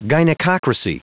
Prononciation du mot gynecocracy en anglais (fichier audio)
Prononciation du mot : gynecocracy